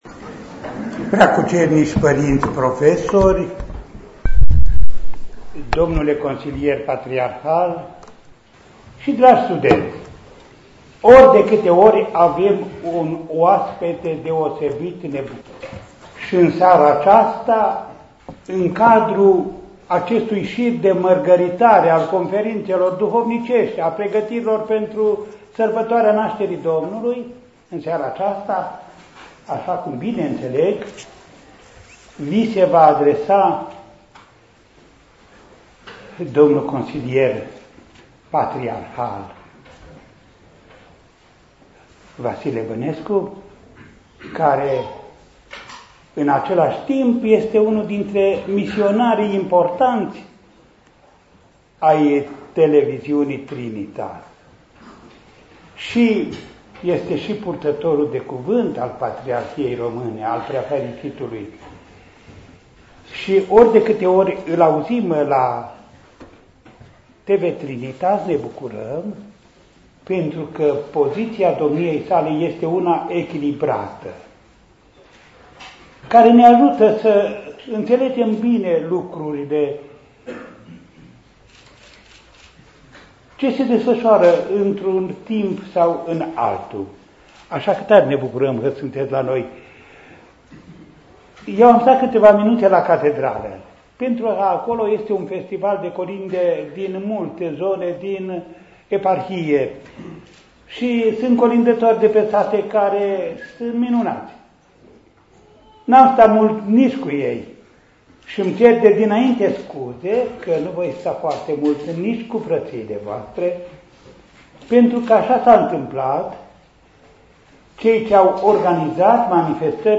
Conferință în Aula mare de la FSEGA